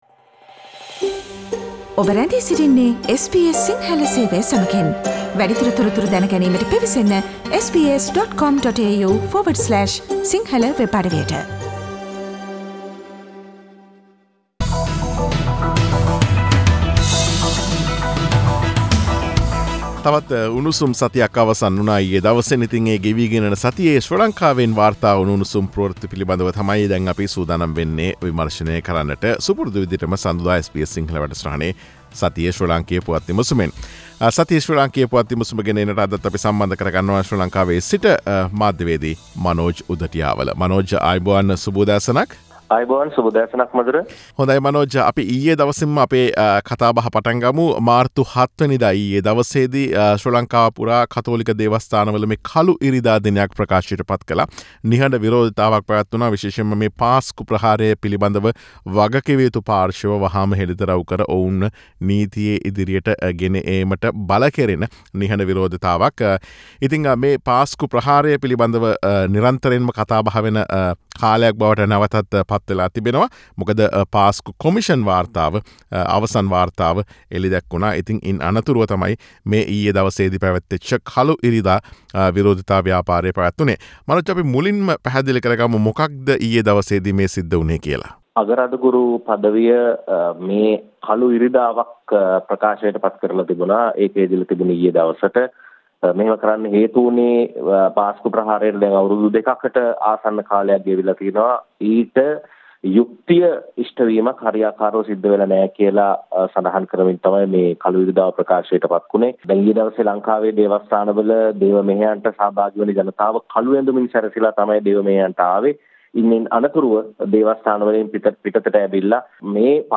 Human Rights Report on Sri Lanka is due to be released on March 09 while Sri Lanka hold Black Sunday protest: Sri Lankan news wrap